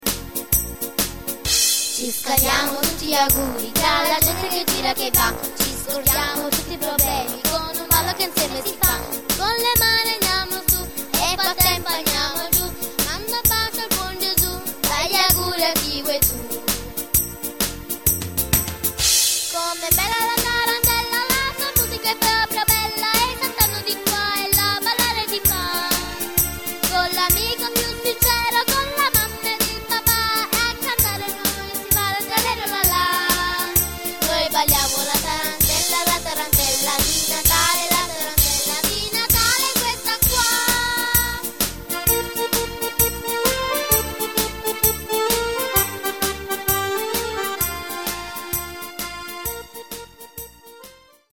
tarantella.mp3